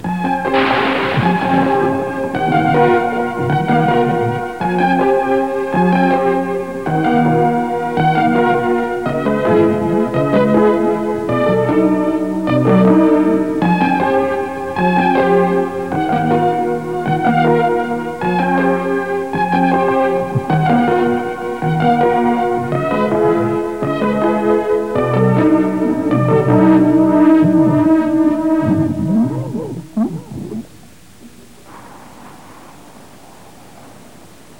Here’s some synth stabbing that’s slightly less interesting.